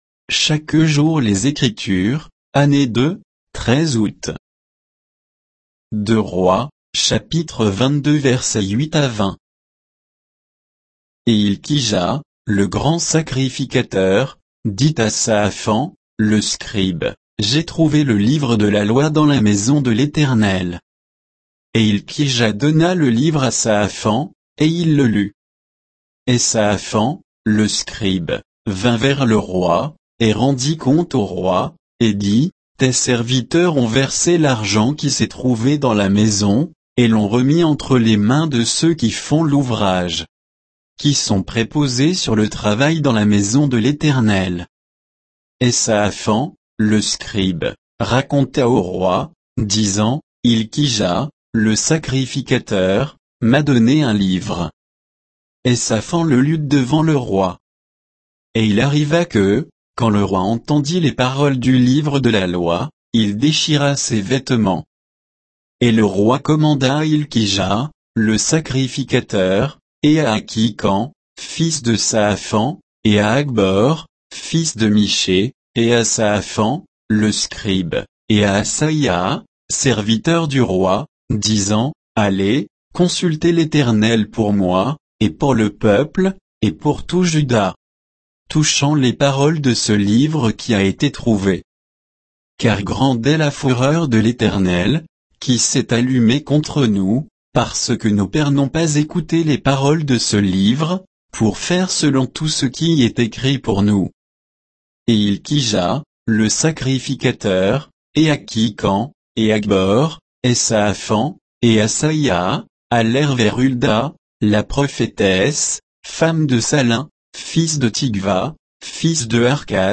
Méditation quoditienne de Chaque jour les Écritures sur 2 Rois 22